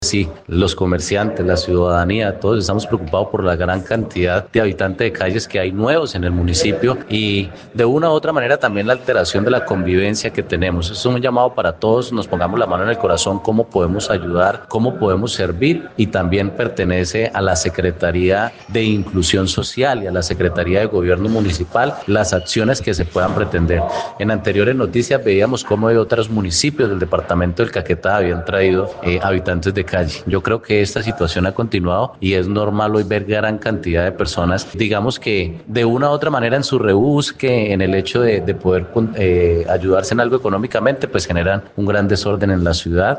Así lo dio a conocer el concejal por Cambio Radical, Leonardo Ramírez Jiménez.